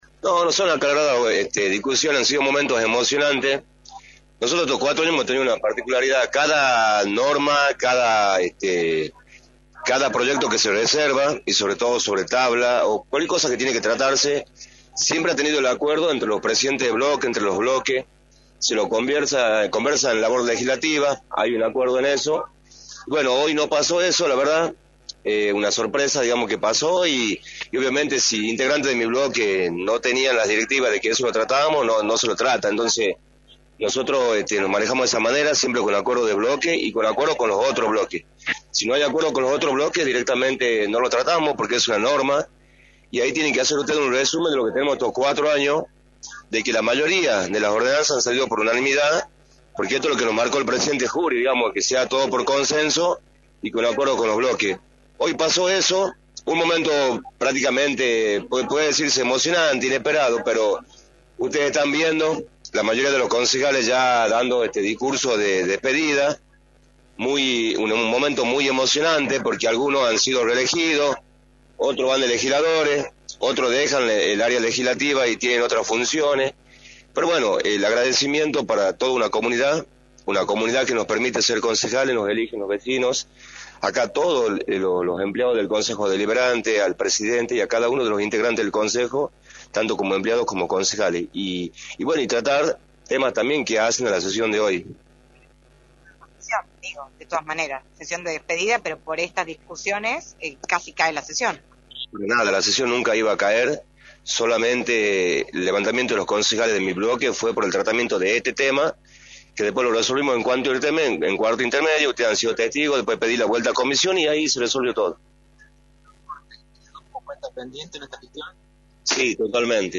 Ernesto Nagle, Concejal de San Miguel de Tucumán, analizó en Radio del Plata Tucumán, por la 93.9, los resultados de su gestión a lo largo de los 4 años como Concejal de la Capital.